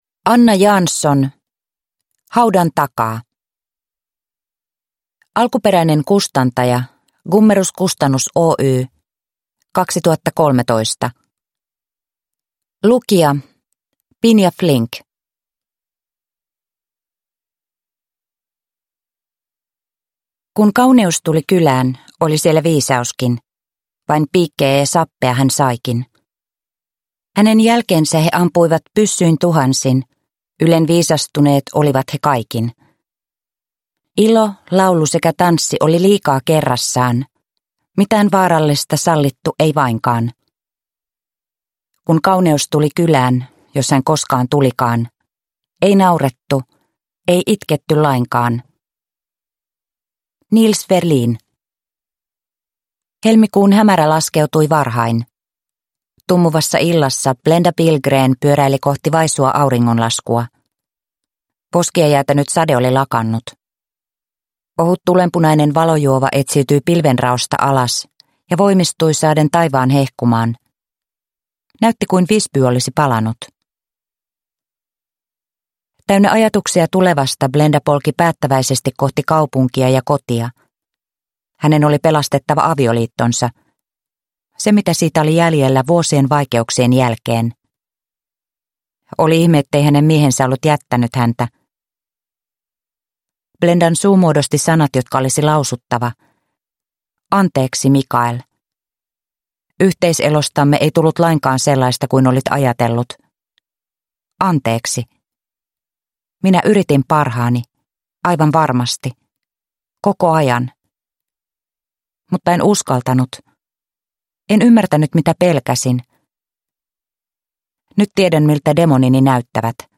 Haudan takaa – Ljudbok – Laddas ner